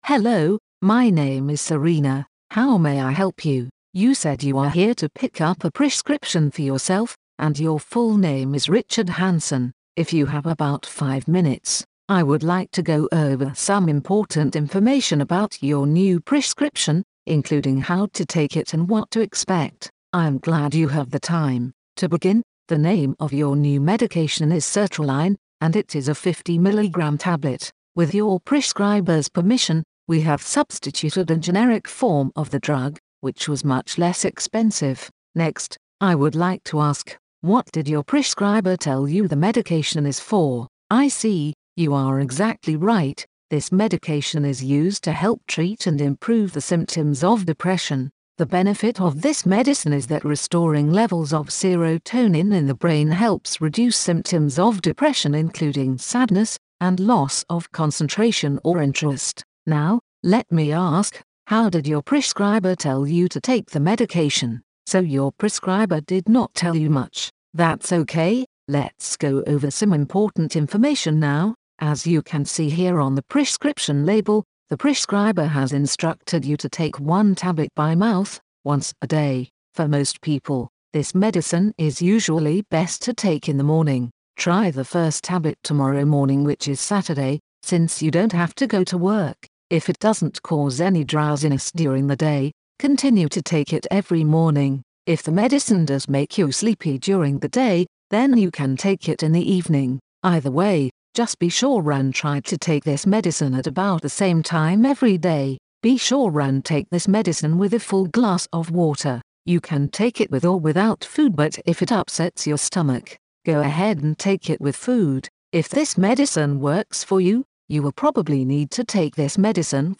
1. Patients were counseled on two common medications following a standardized counseling format.